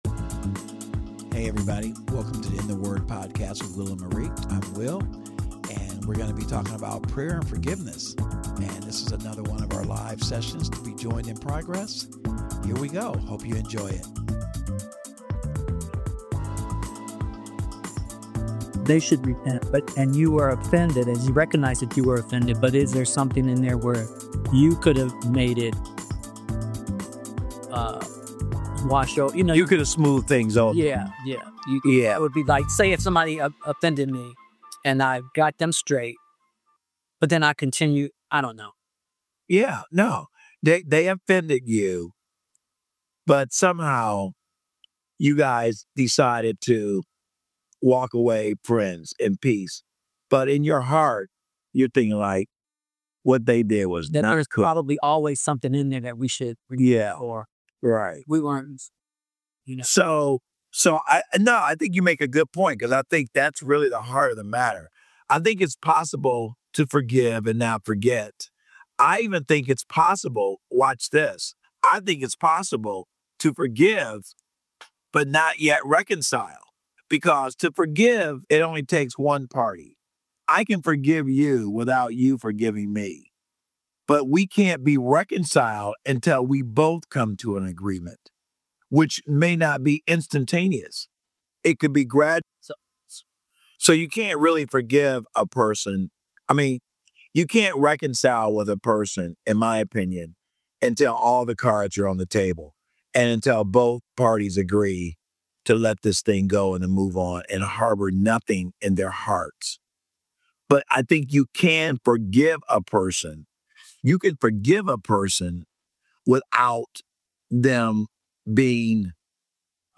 • Don’t Let it Smolder: The hosts talk about “nipping it in the bud”.